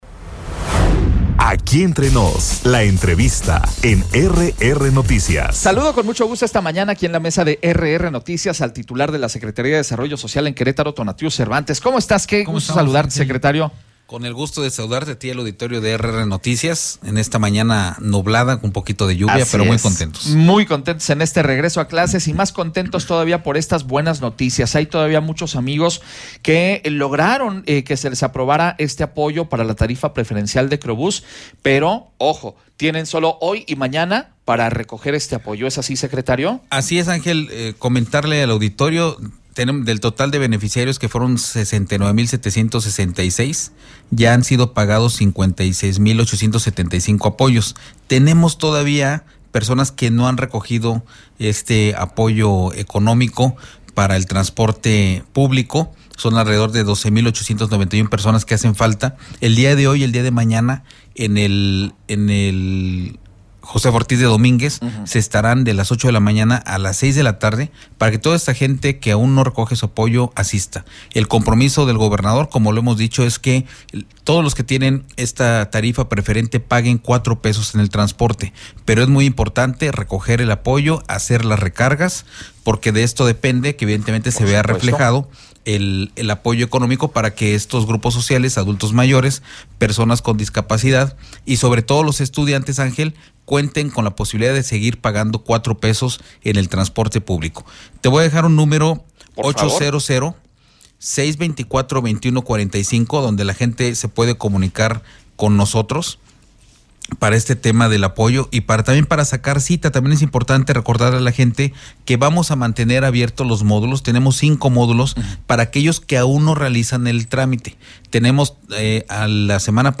ENTREVISTA-TONATIUH-CERVANTES-TITULAR-SEDESOQ.mp3